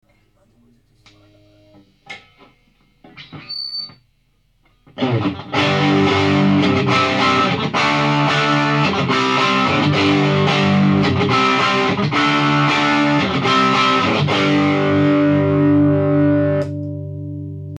録音したMDから雑音のひどいものを取り除き、アップだば。
10.GUITARDER　YJM308